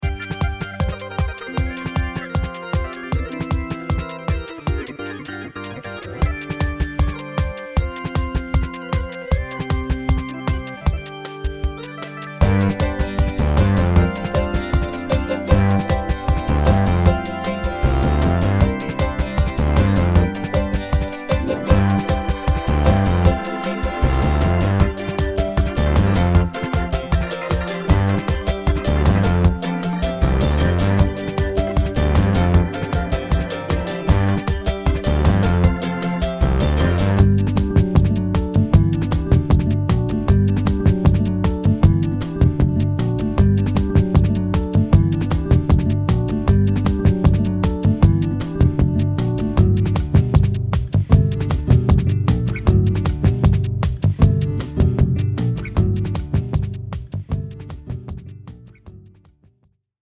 が、ソフトに入っている音源（というかいわゆるループ）が結構豊富なので、このアルバムではほとんど演奏はせず、既成のループを足し合わせて楽曲もどきを作っています。
ほとんどの楽曲で演奏はしていません。ただ組み合わせているだけです。